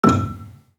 Gambang-E5-f.wav